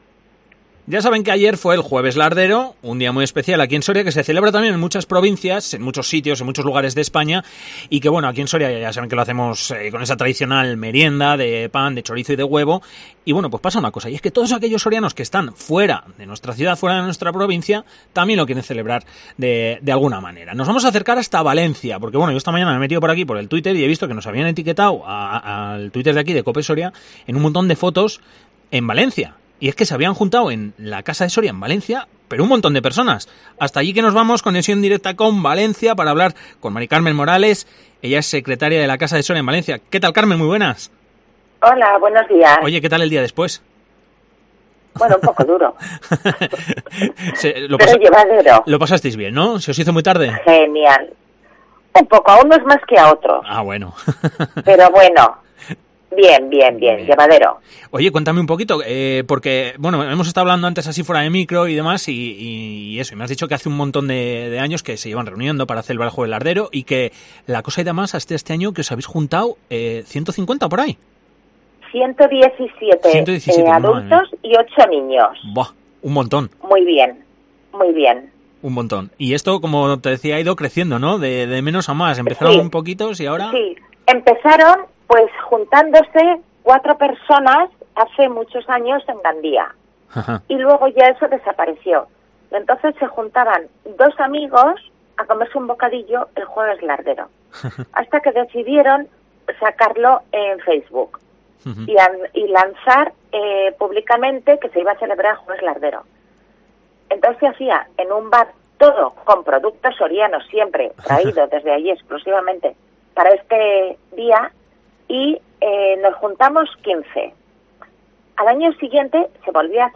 Entrevista Casa de Soria en Valencia